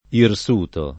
[ ir S2 to ]